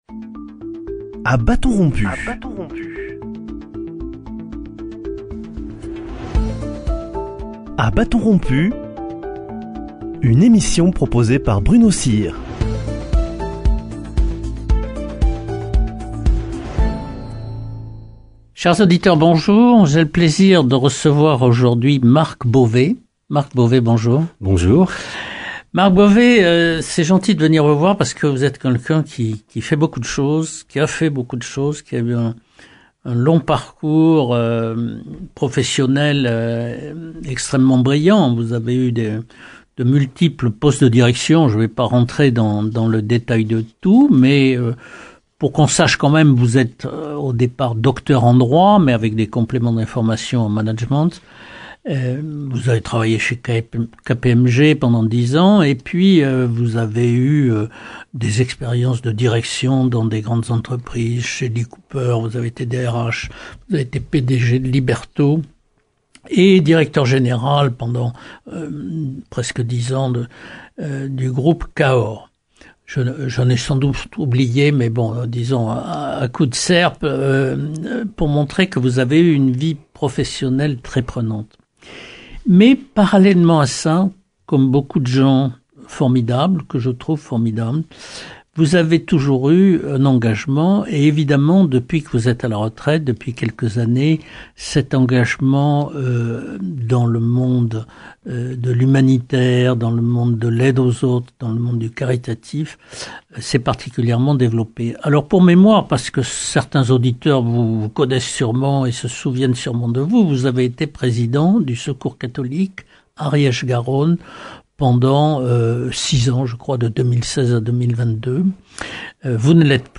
Il nous explique, dans ce premier entretien, comment on peut passer du monde de l’entreprise dans un univers très concurrentiel au monde associatif.